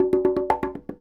44 Bongo 18.wav